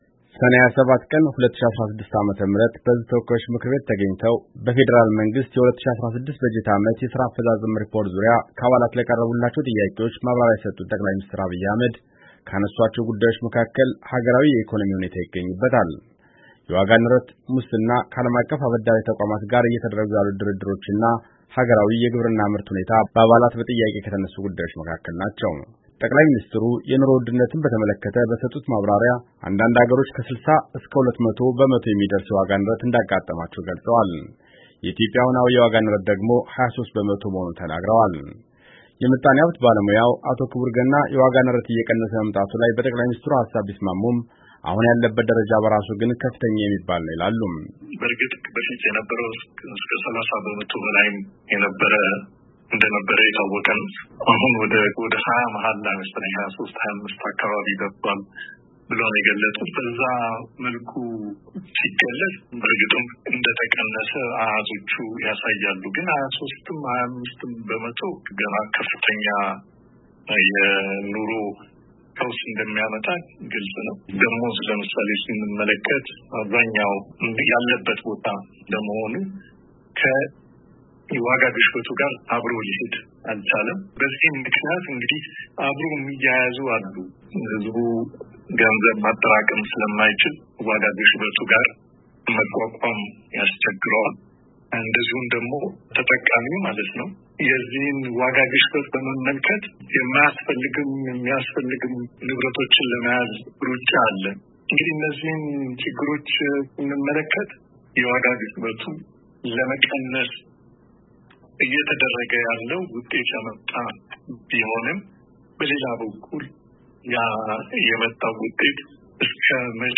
በውጭ ምንዛሬ እጥረት እየተፈተነች ባለቸው ኢትዮጵያ ተጨማሪ ብድር መገኘቱ ኢኮኖሚውን ሊያነቃቃው እንደሚችል የሚናገሩት የአሜሪካ ድምጽ ያናገራቸው የምጣኔ ሃብት ባለሙያዎች ነገር ግን ይህም ተጨማሪ እዳ ለሃገሪቱ እንዳያስከትል ከወዲሁ ትክክለኛ የልማት ስራዎች መለየት ያስፈልጋል ይላሉ::